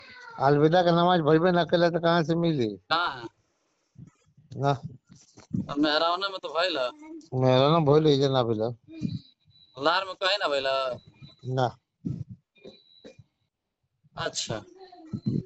खीरी मुहल्ला मस्जिद में पढ़ी गयी नमाज